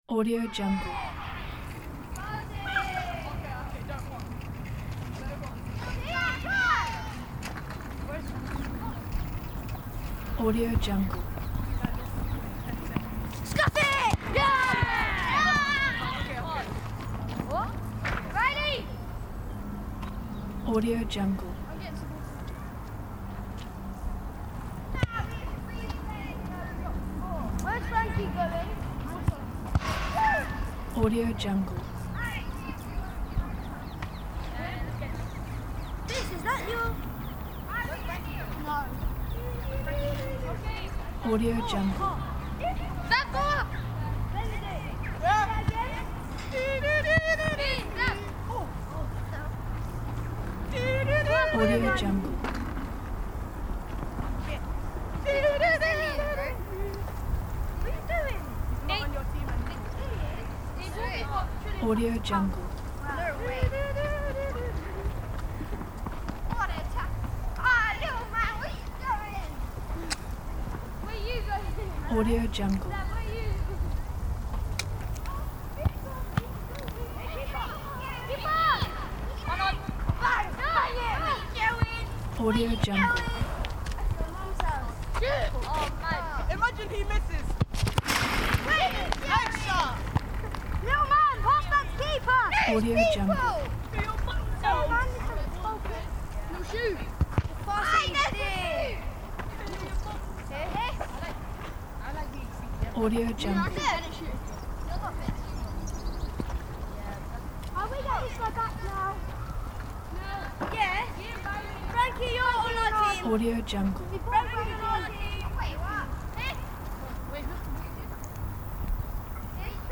دانلود افکت صدای فوتبال بازی کردن بچه ها
دانلود افکت صوتی شهری
به دنیای پر انرژی و شاد فوتبال کودکان خوش آمدید!
• ایجاد جو شاد و پر انرژی: صدای شوت زدن توپ، خنده‌های بلند بچه‌ها، تشویق‌ها و فریادهای شادی، حس شادابی و نشاط را به بیننده منتقل می‌کند و می‌تواند برای ایجاد جوهای شاد، خانوادگی یا ورزشی در ویدیوهای شما استفاده شود.
• کیفیت بالا: ضبط شده با بهترین تجهیزات صوتی
16-Bit Stereo, 44.1 kHz